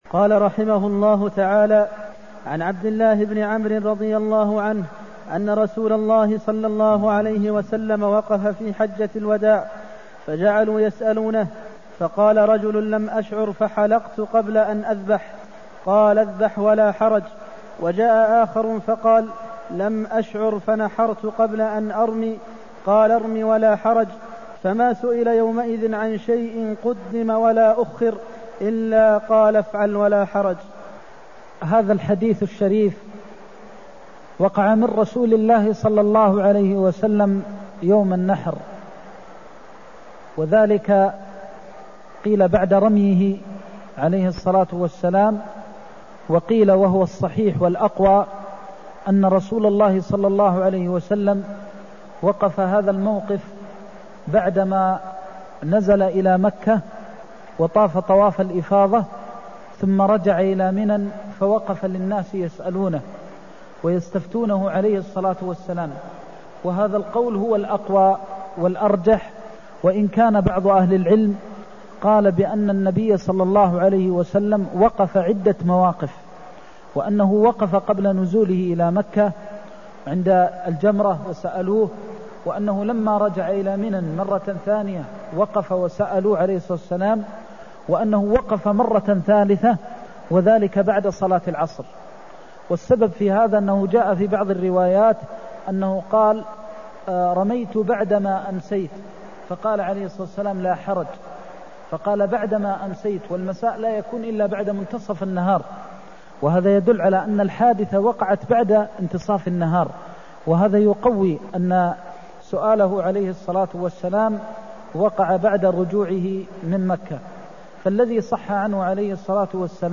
المكان: المسجد النبوي الشيخ: فضيلة الشيخ د. محمد بن محمد المختار فضيلة الشيخ د. محمد بن محمد المختار افعل ولا حرج (234)